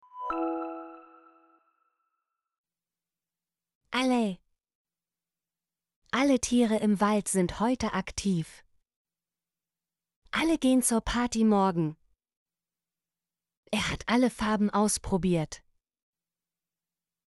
alle - Example Sentences & Pronunciation, German Frequency List